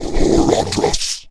spawners_mobs_mummy_spell.1.ogg